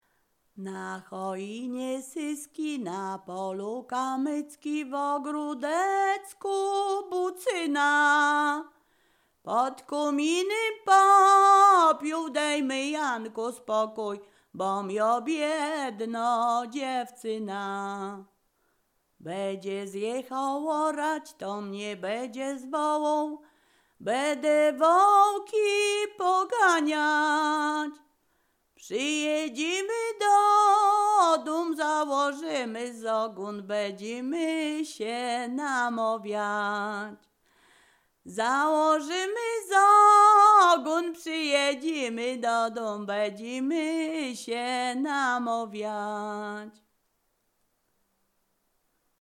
Ziemia Radomska
liryczne miłosne weselne wesele przyśpiewki